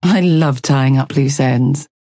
Calico voice line - I love tying up loose ends.